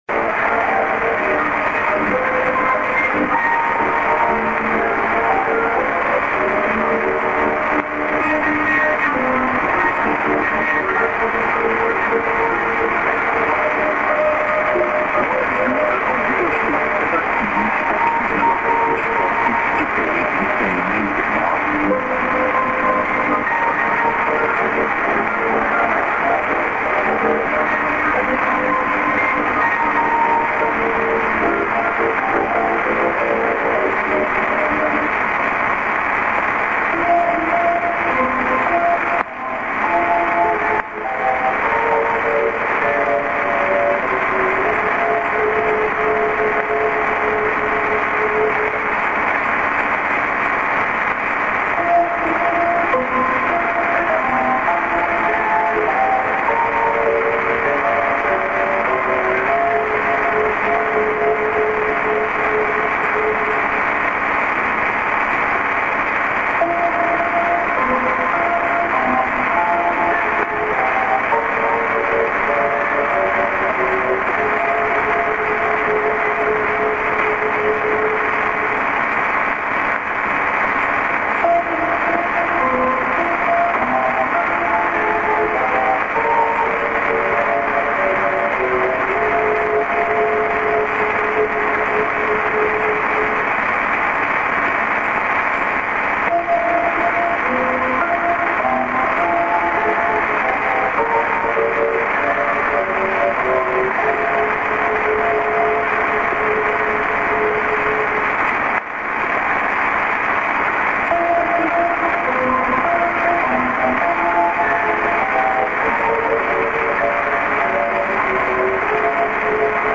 music->ANN(man)->IS-> s/off
前日より受信状態は良くありません。